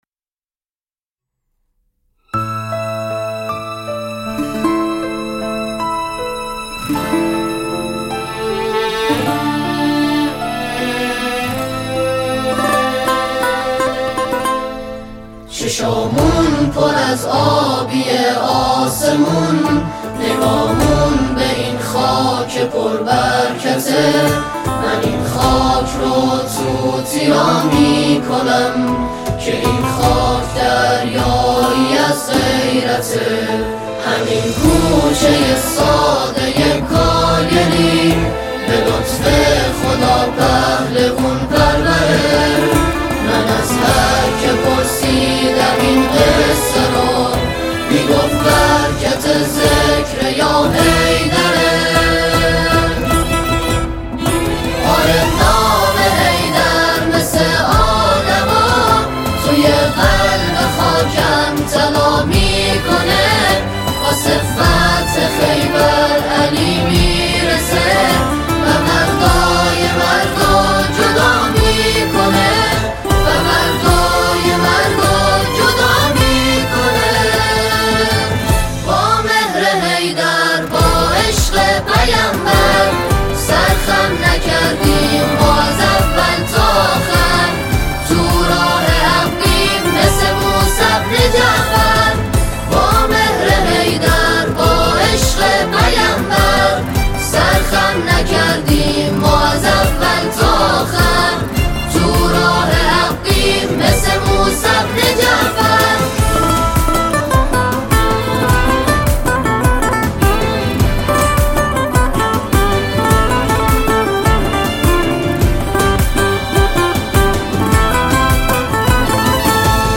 نماهنگ حماسی
اثری پرشور و روح‌افزا
ژانر: سرود